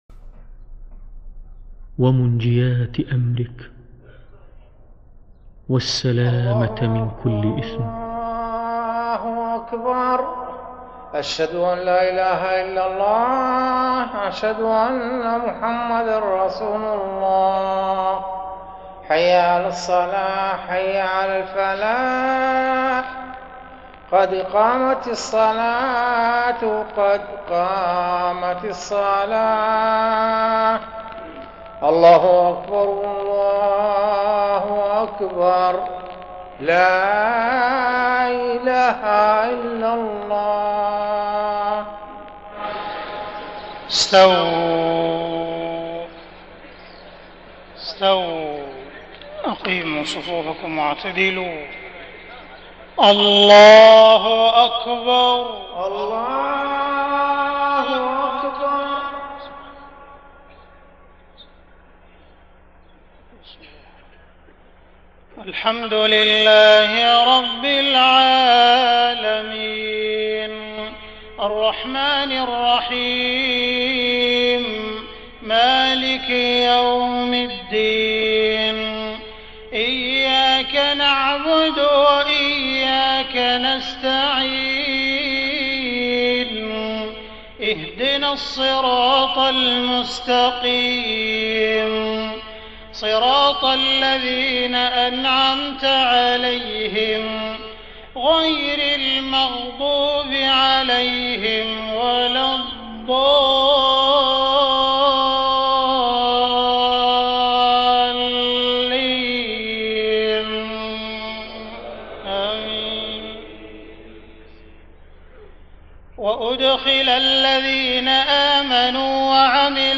صلاة المغرب 2 محرم 1430هـ من سورة إبراهيم 23-31 > 1430 🕋 > الفروض - تلاوات الحرمين